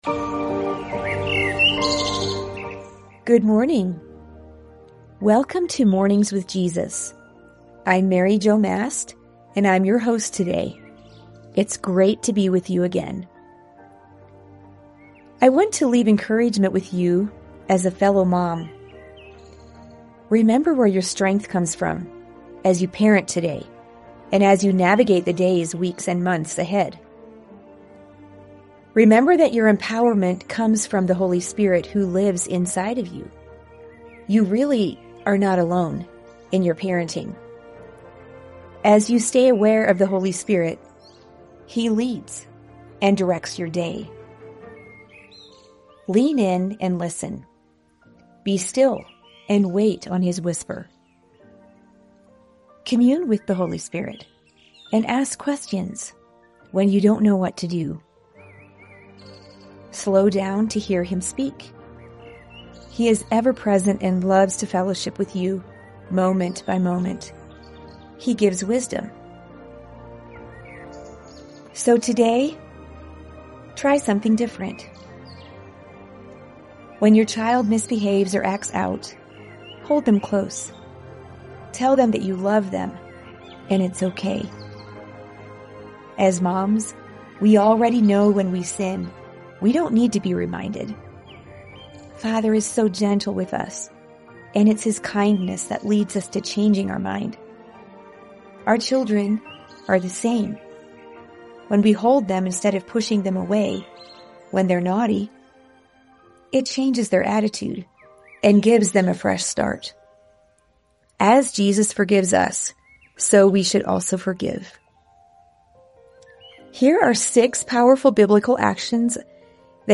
Mornings With Jesus: A Five- Day Audio Devotional Plan for Moms
🌿 Through gentle prayers, Scripture, and Spirit-led encouragement, Mornings with Jesus invites you to slow down, listen, and walk closely with the One who knows you best.